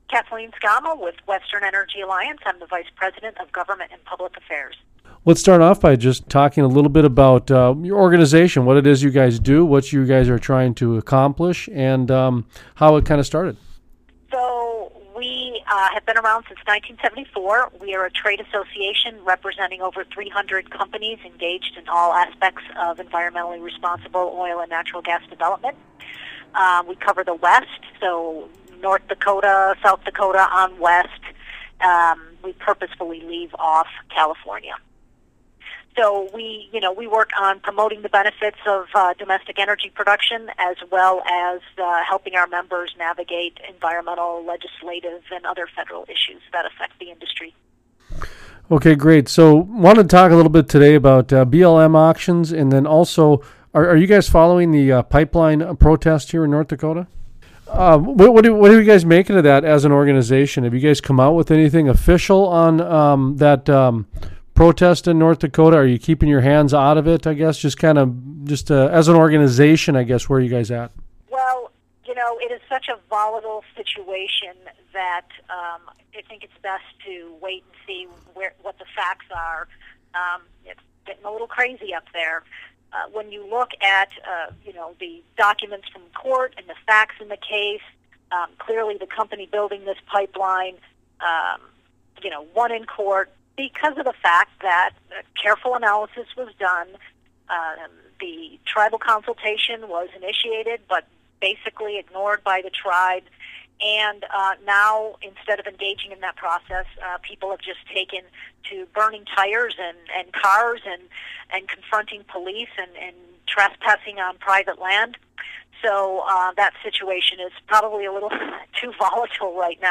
(Audio Interview from November 2016)